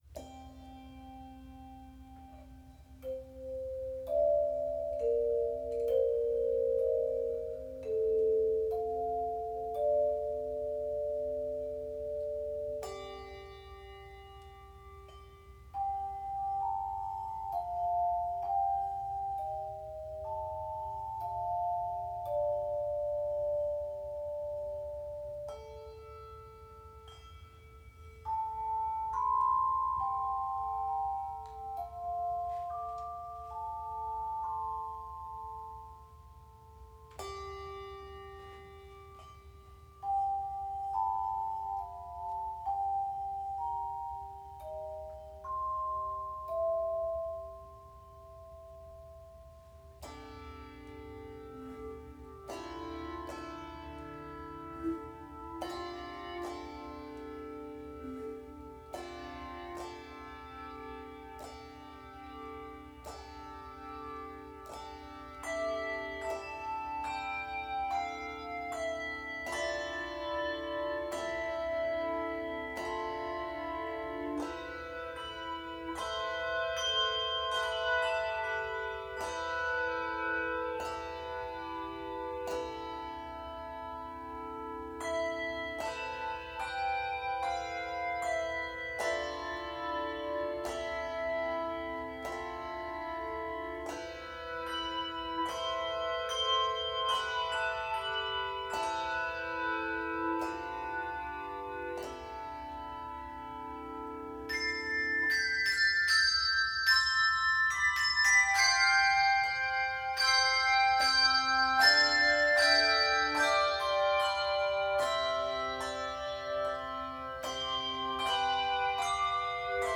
Key of a minor.